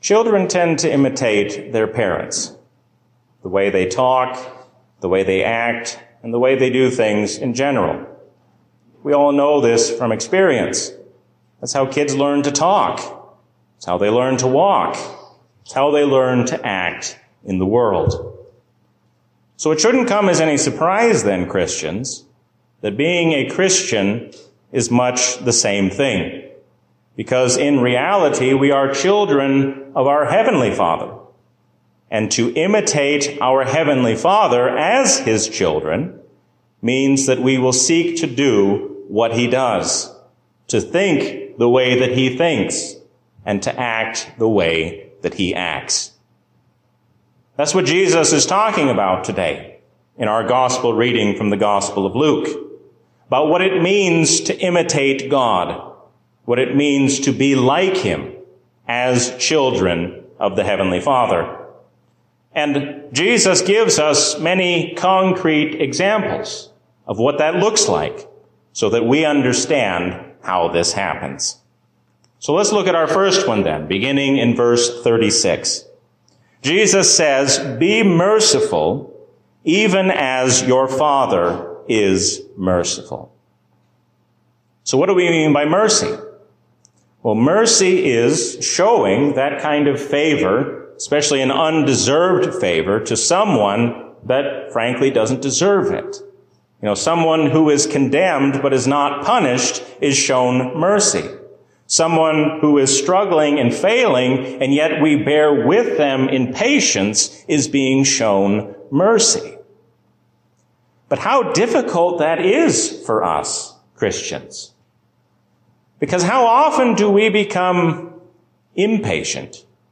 A sermon from the season "Trinity 2023." Listen to Jesus and do what He says, because He is your loving Lord.